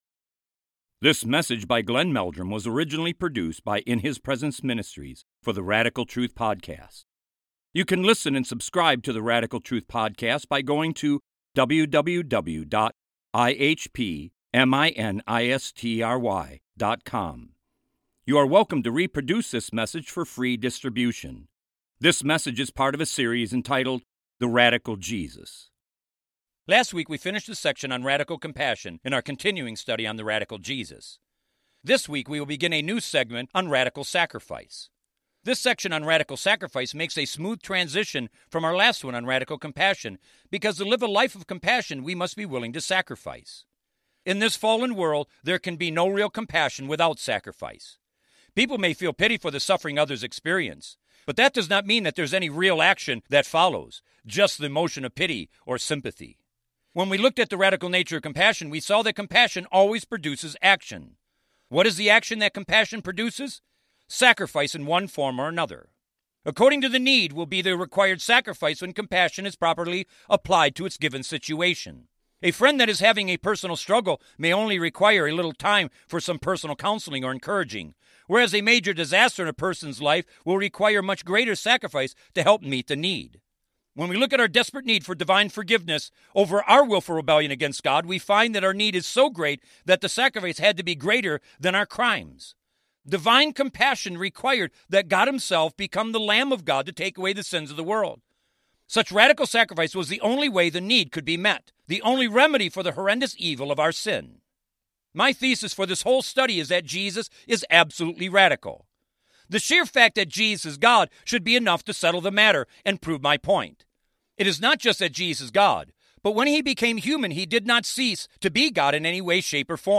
In this sermon, the preacher emphasizes the power and significance of Christ's radical sacrifice. Through his sacrifice, Christ accomplished the work of redemption and opened the doors of heaven for sinners. The preacher highlights the parable of the Pharisee and the tax collector as a powerful illustration of the atonement and the way to obtain salvation and help from God.